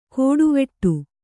♪ kōḍuveṭṭu